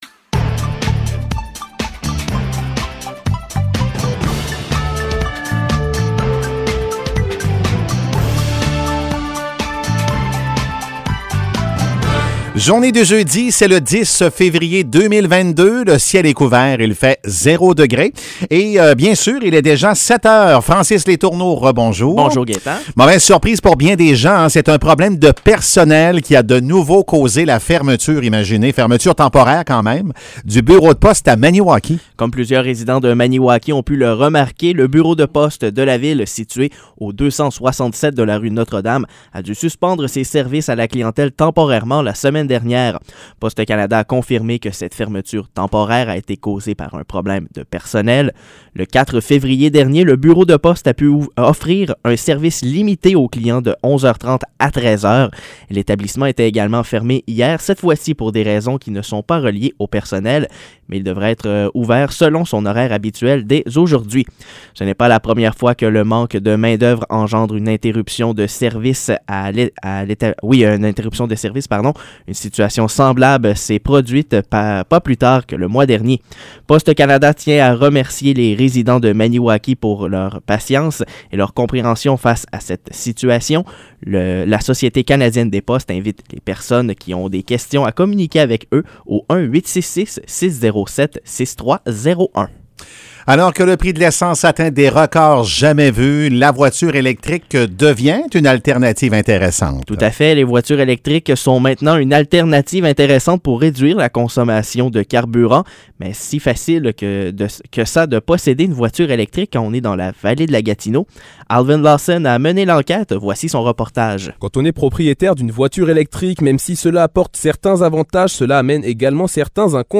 Nouvelles locales - 10 février 2022 - 7 h